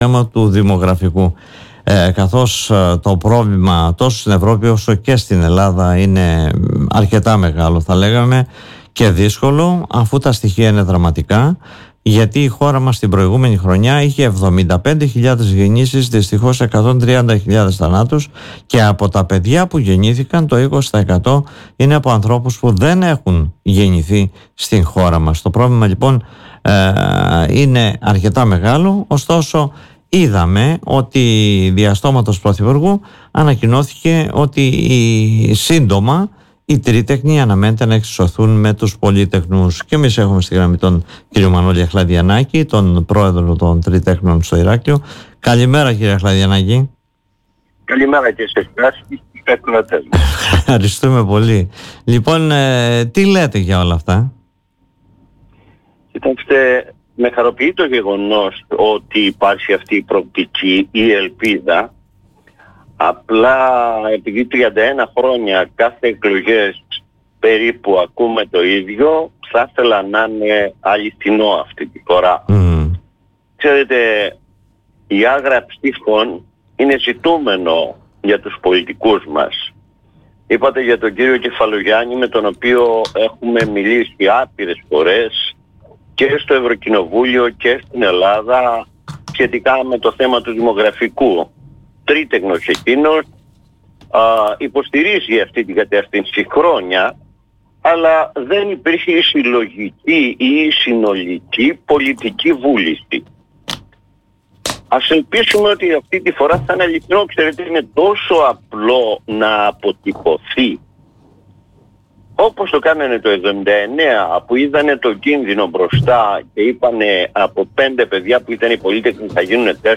μιλώντας στην εκπομπή “Δημοσίως” του politica 89.8